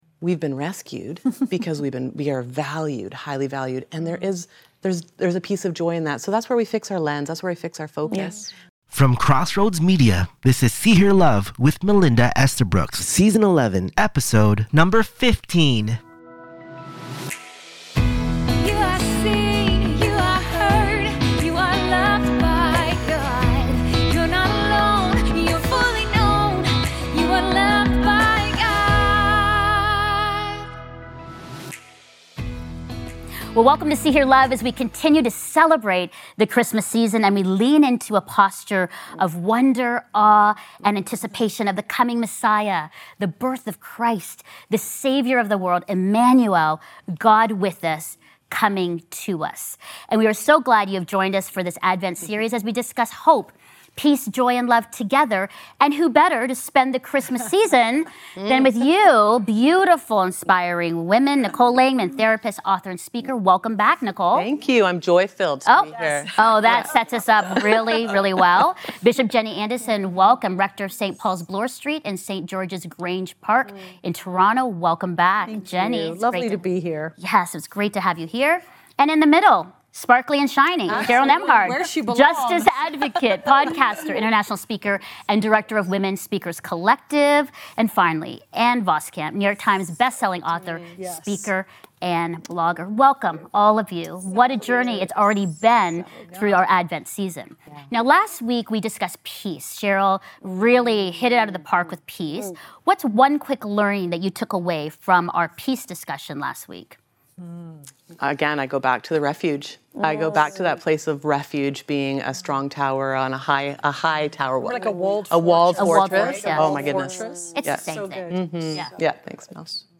In this Advent conversation